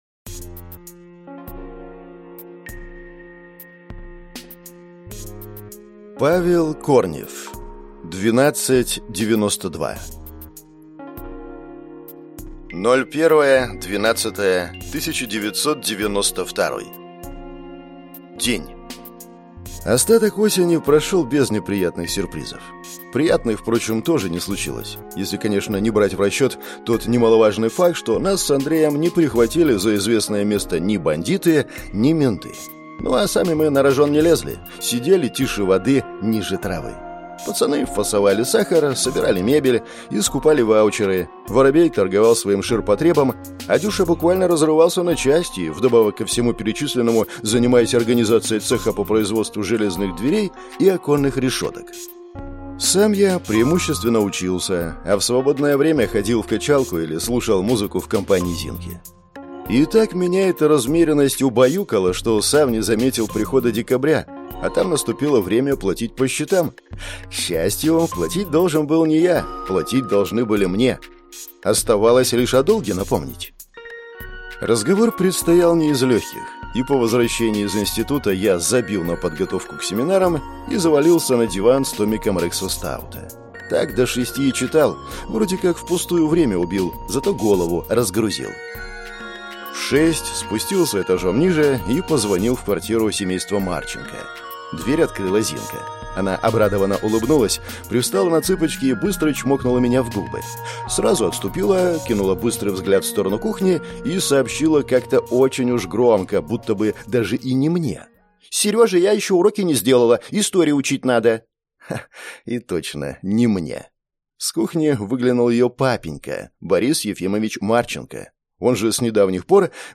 Аудиокнига 12'92 | Библиотека аудиокниг